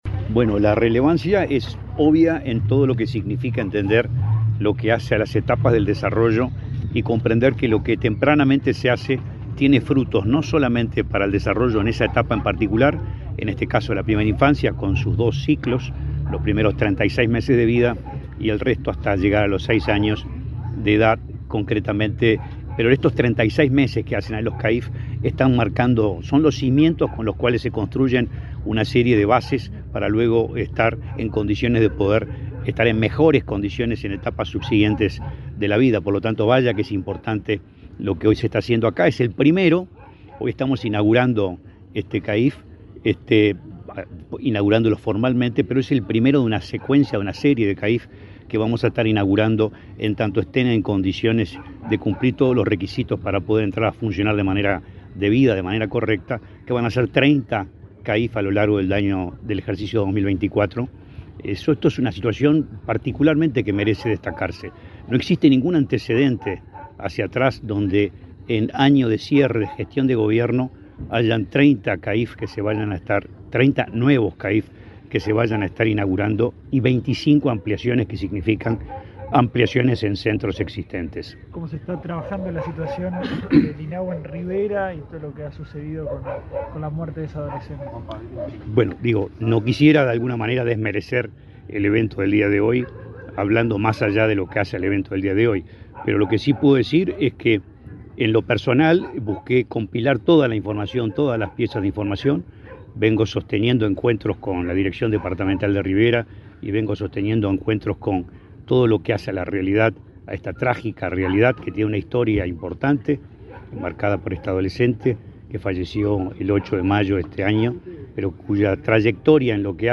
Declaraciones del presidente del INAU, Guillermo Fossati
Declaraciones del presidente del INAU, Guillermo Fossati 19/07/2024 Compartir Facebook X Copiar enlace WhatsApp LinkedIn El presidente del Instituto del Niño y Adolescente del Uruguay (INAU), Guillermo Fossati, fue entrevistado por medios informativos, luego de participar de la inauguración de centro de atención a la infancia y la familia (CAIF), en Puntas de Manga, departamento de Montevideo.